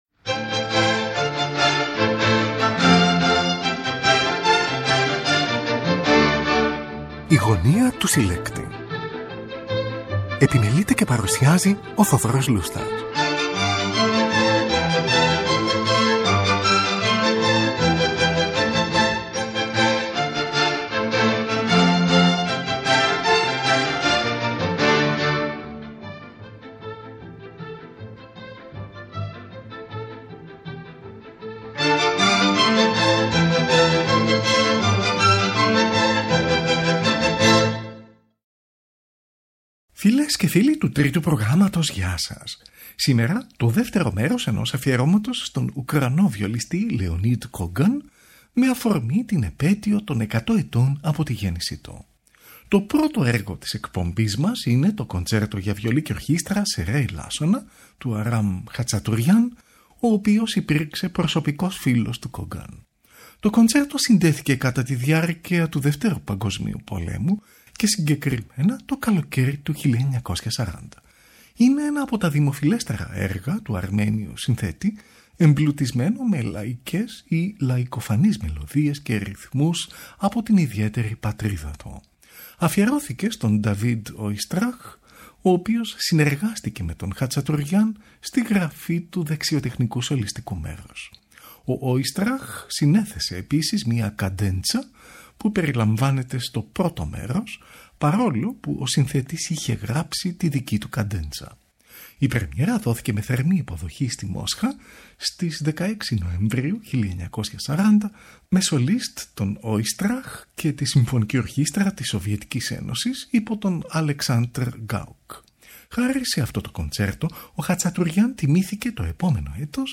Κοντσέρτο για Βιολί και Ορχήστρα
από ζωντανή ηχογράφηση
για Βιολί και Πιάνο